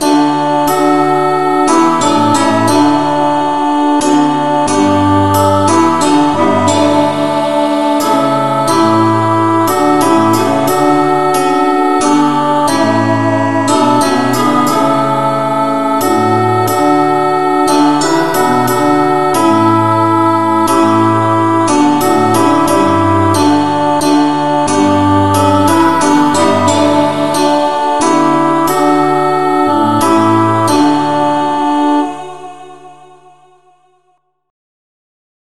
ineedthee-revnovocal-keyd.mp3